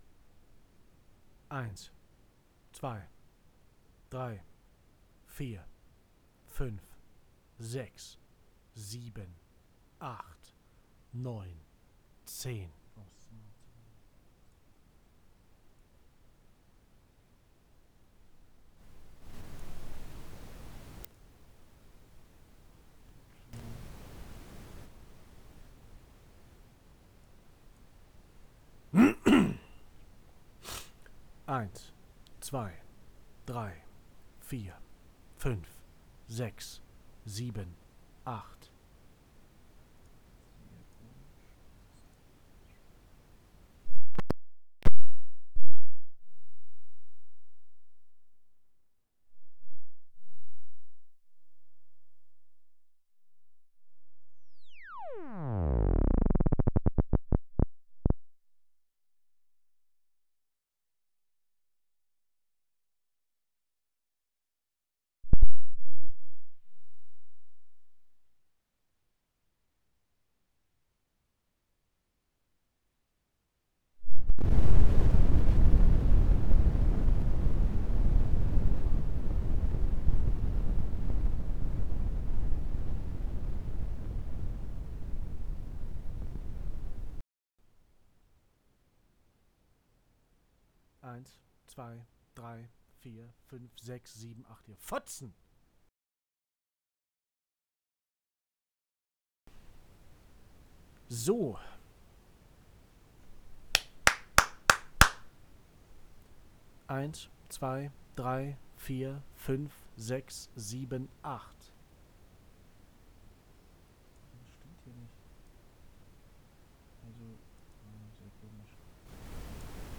Mikrofon rauscht
Deshalb hier nochmal: Meine Vermutung: Das RME hat einen Defekt, ich glaube nicht, dass es am Mikro liegt.
Da wo du nicht sprichst ist eindeutig reichlich Rauschen zu sehen.